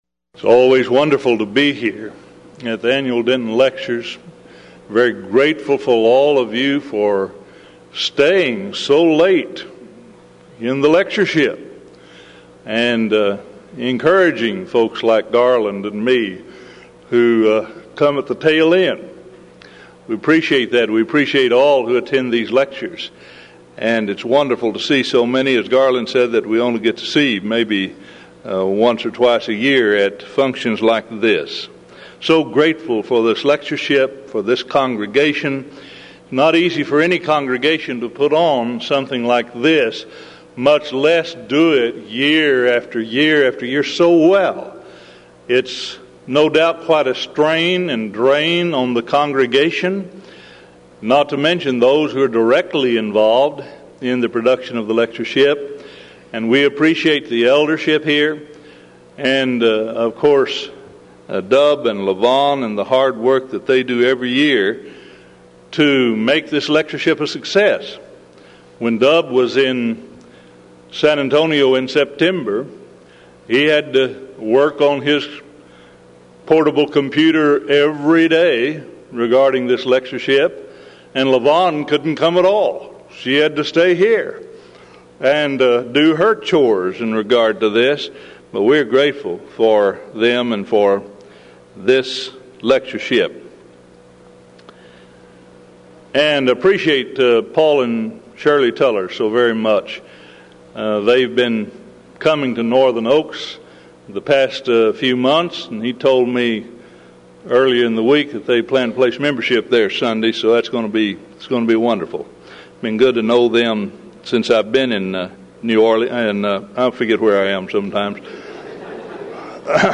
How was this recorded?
Event: 1995 Denton Lectures